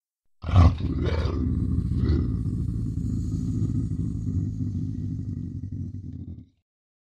Soundscape Overhaul / gamedata / sounds / monsters / cat / c2_idle_2.ogg